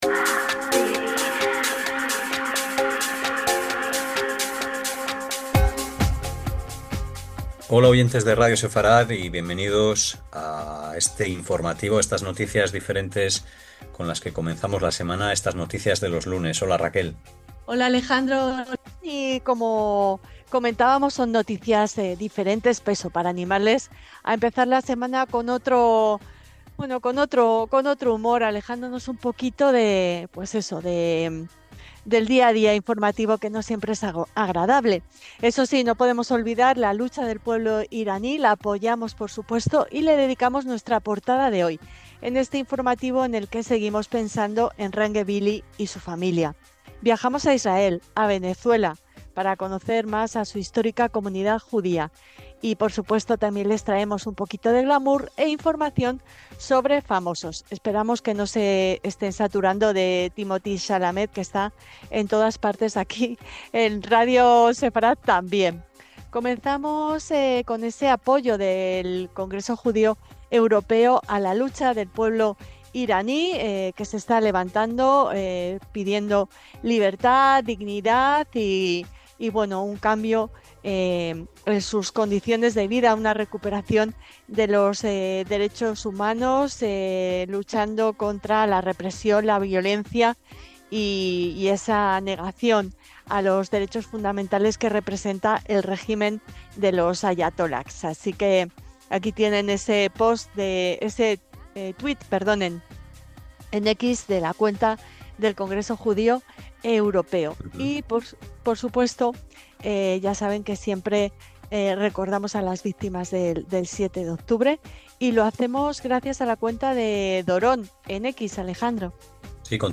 LAS NOTICIAS DE LOS LUNES - Apoyamos la lucha del pueblo de Irán, y le dedicamos nuestra portada de hoy en este informativo